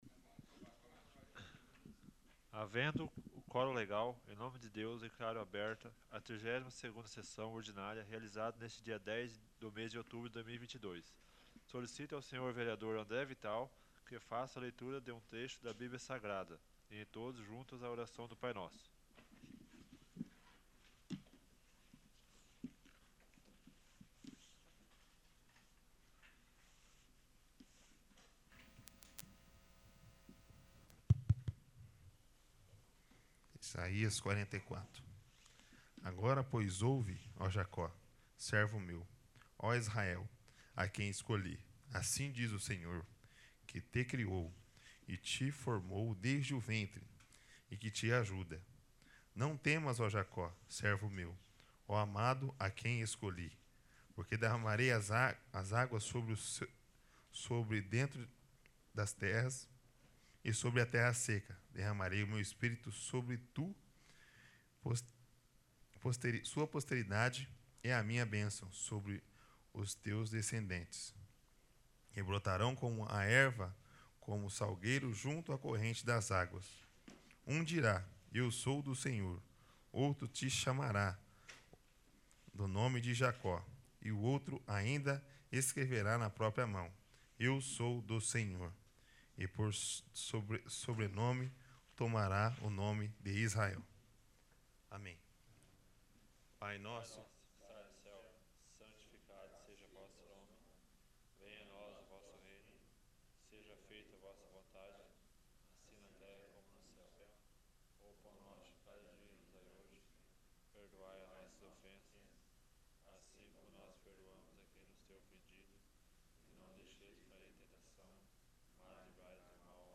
32º. Sessão Ordinária